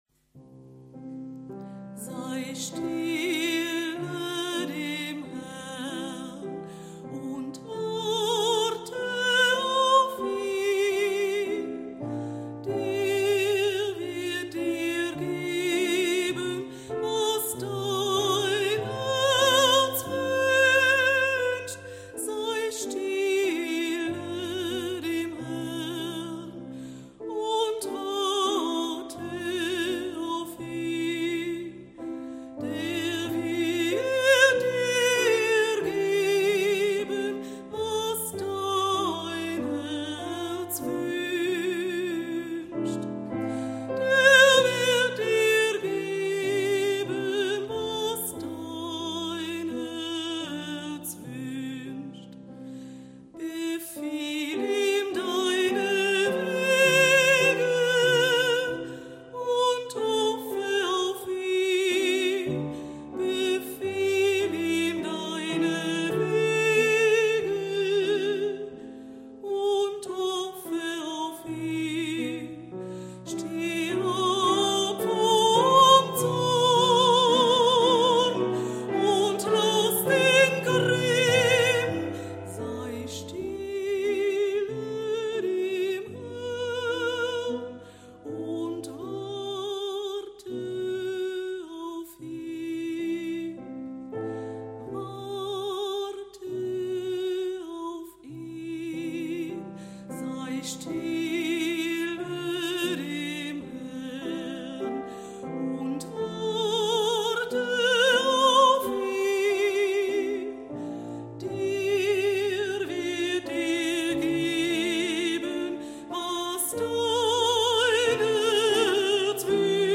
Sängerin
Klavier